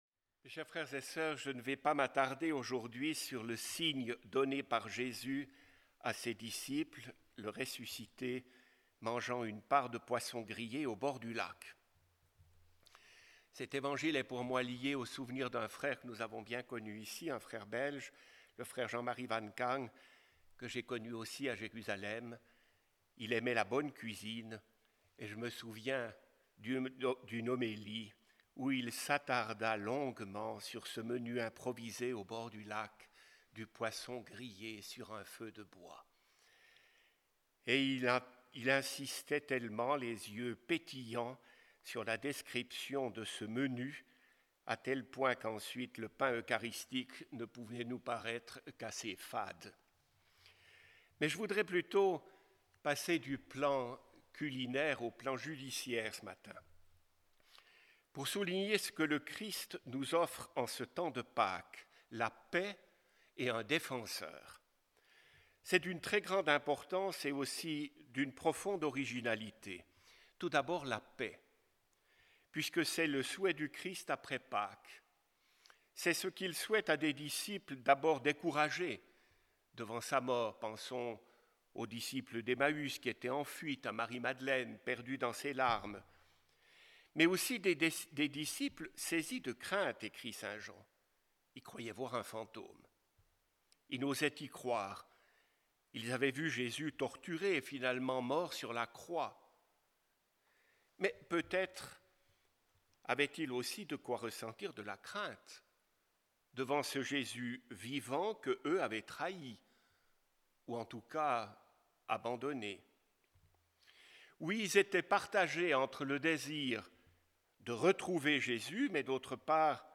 Pour ce troisième dimanche de Pâques